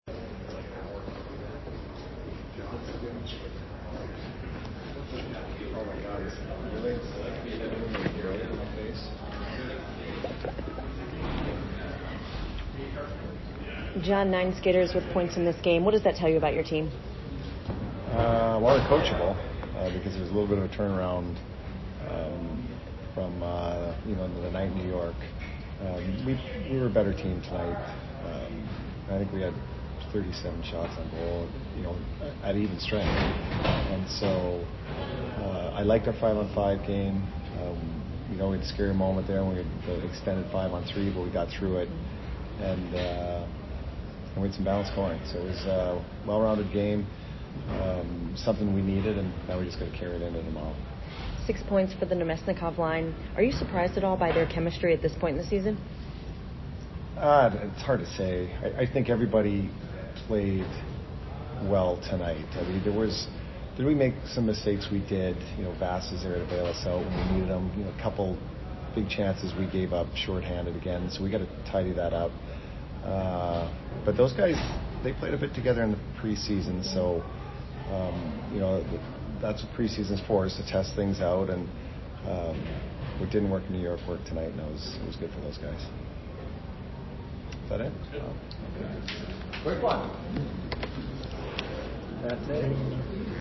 Head Coach Jon Cooper Post Game 10/14/22 @ CBJ